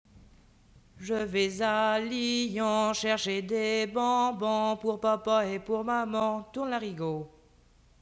Emplacement Saint-Pierre